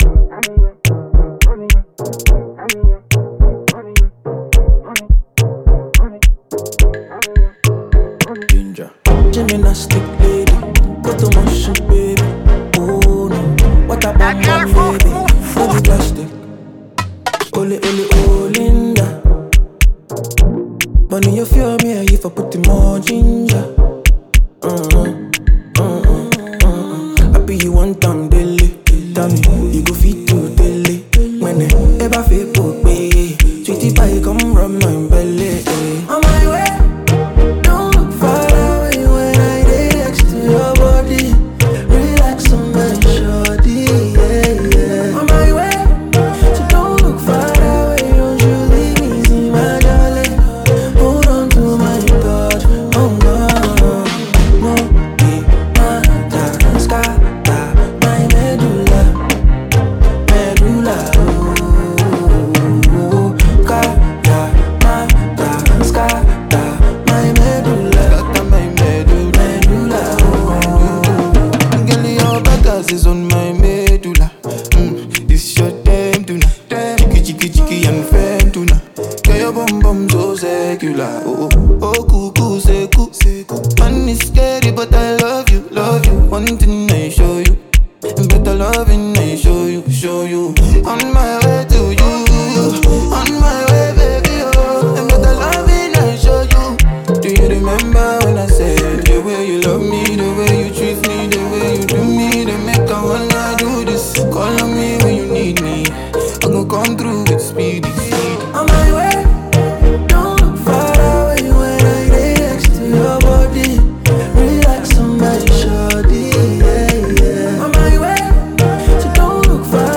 a super-talented Ghanaian singer.